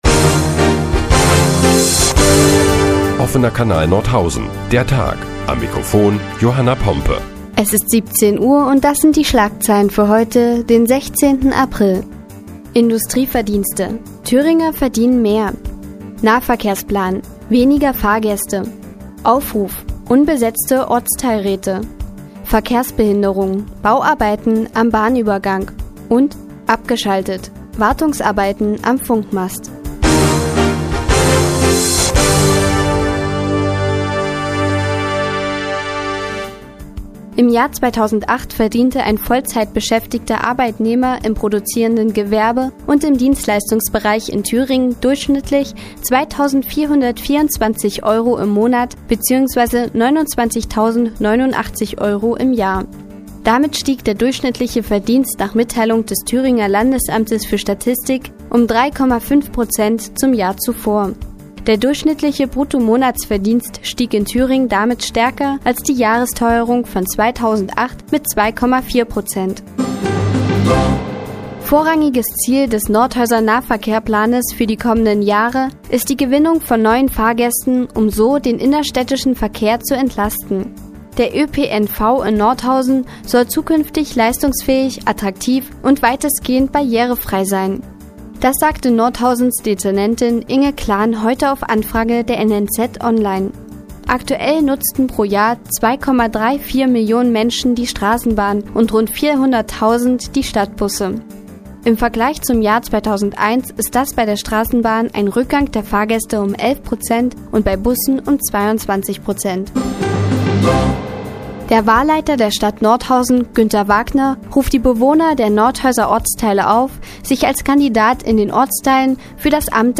Die tägliche Nachrichtensendung des OKN ist nun auch in der nnz zu hören. Heute geht es unter anderem um die Thüringer Industrieverdienste und weitere Verkehrsbehinderungen in der Freiherr-vom-Stein-Straße.